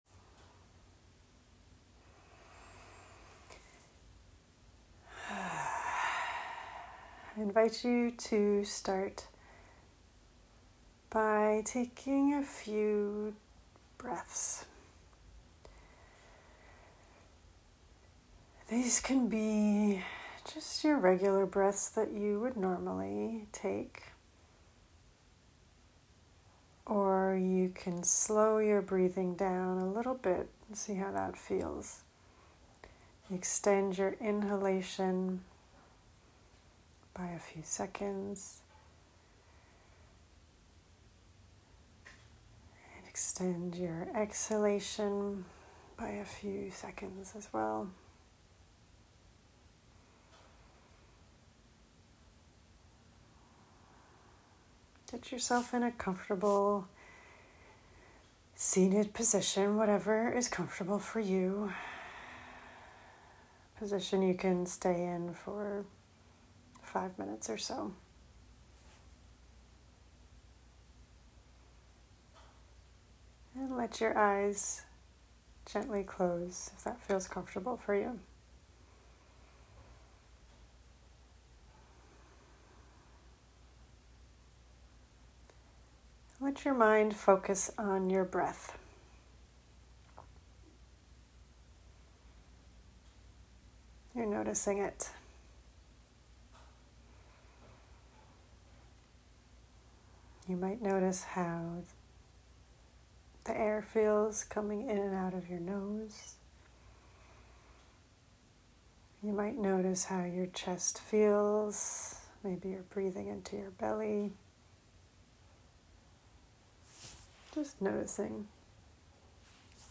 🔻New Meditation Guide 🎧 Into the Garden Meditation